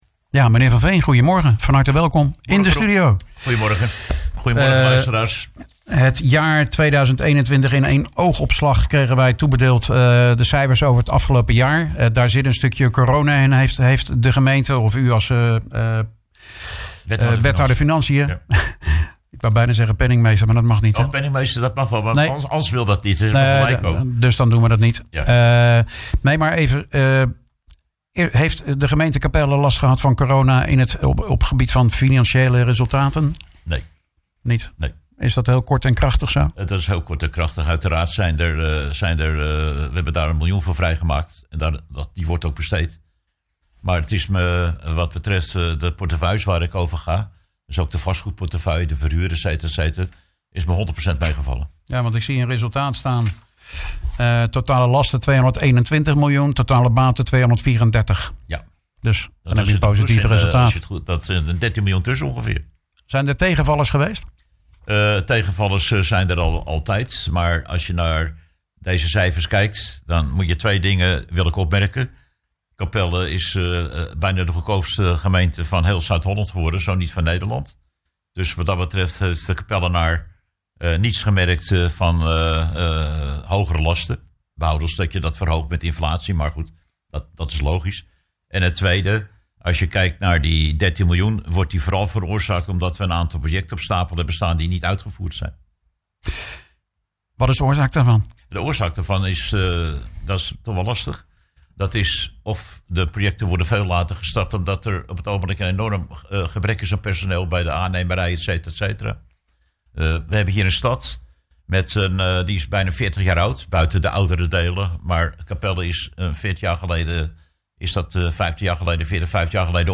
in gesprek met demissionair wethouder Nico van Veen